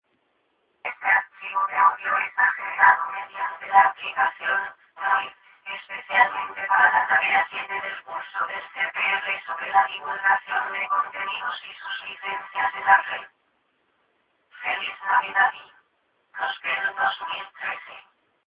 Sonido generado mediante aplicación android